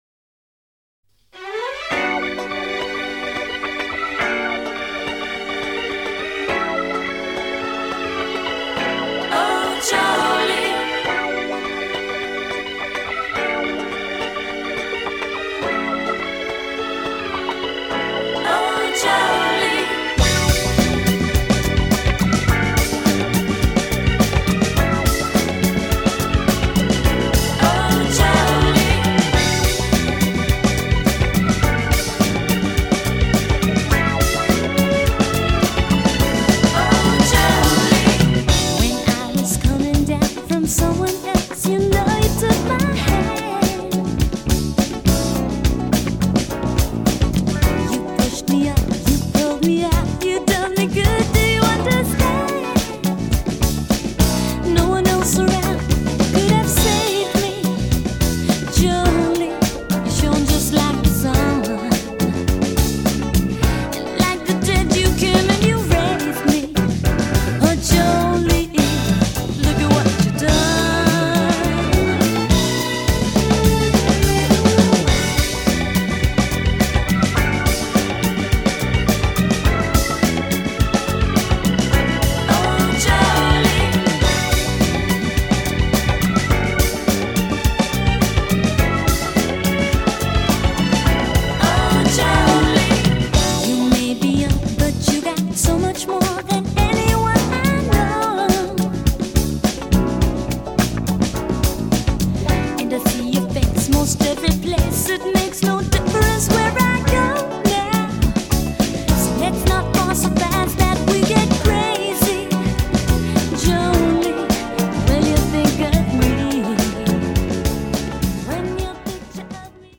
ジャンル(スタイル) JAPANESE POP / FREE SOUL / HOUSE